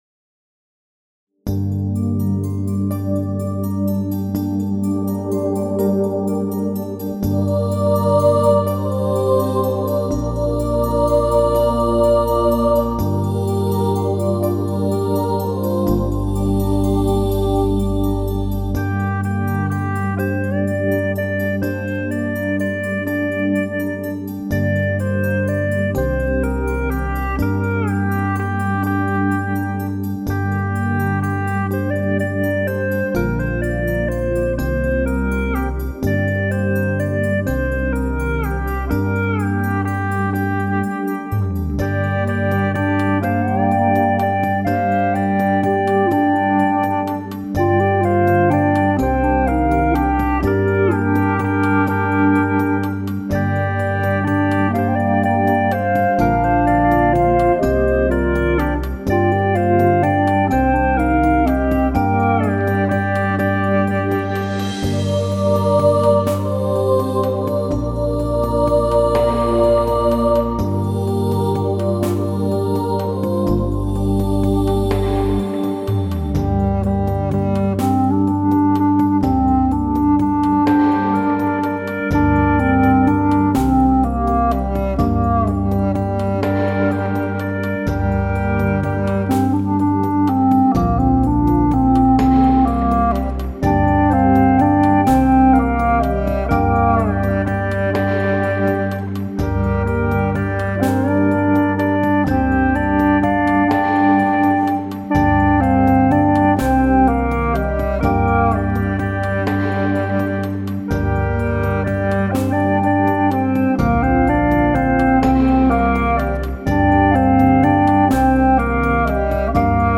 调式 : 降B 曲类 : 民族